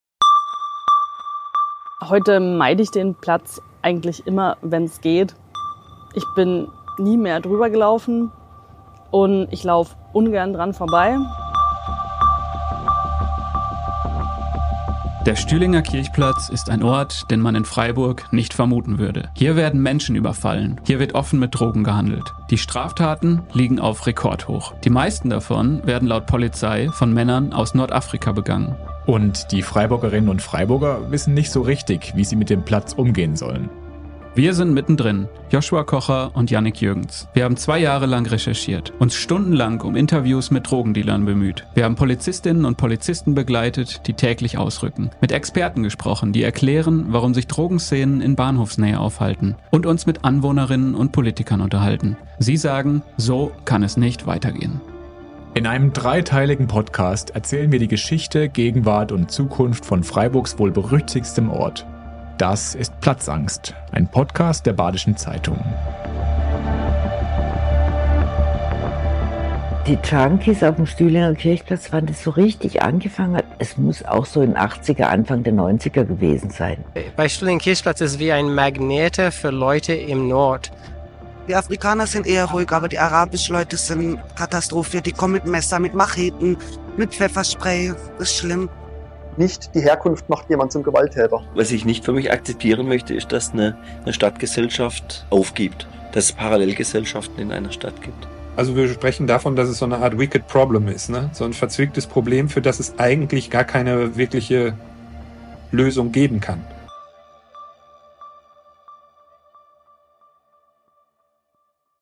Sie sprechen mit Anwohnern, ehemaligen